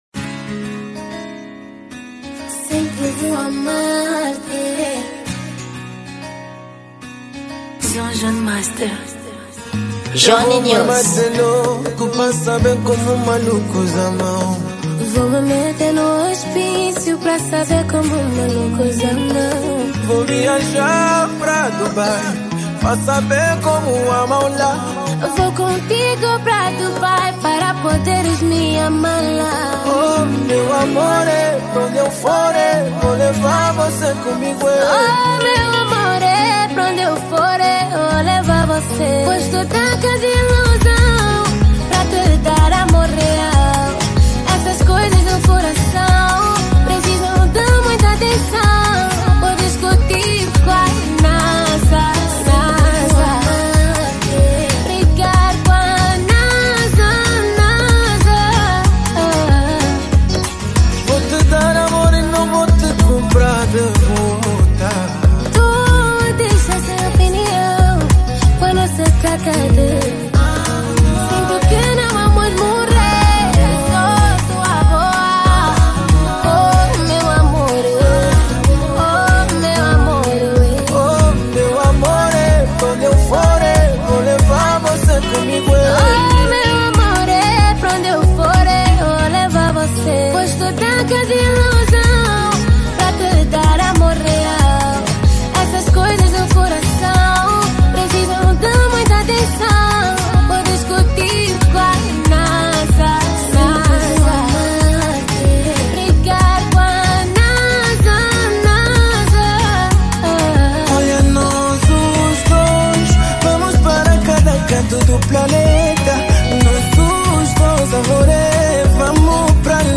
Gênero: Zouk